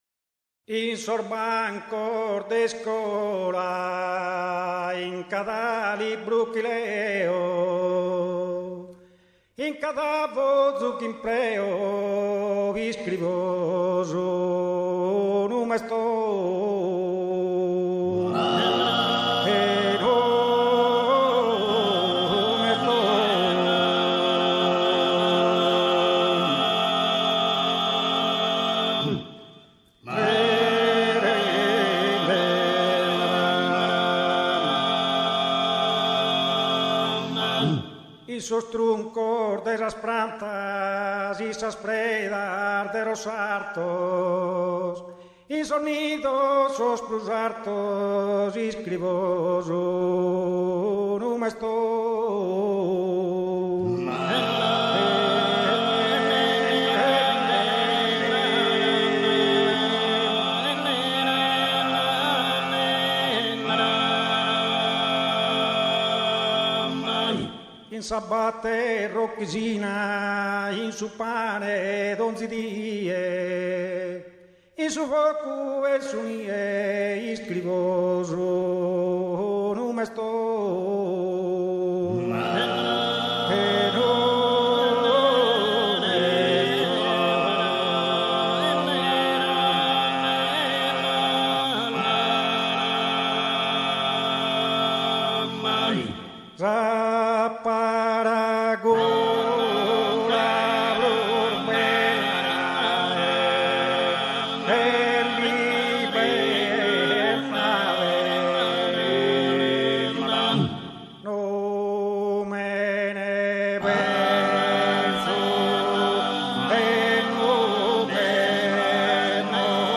Libertade - Conservatorio - Canepa Sassari
06-Libertade-ballu-tundu.mp3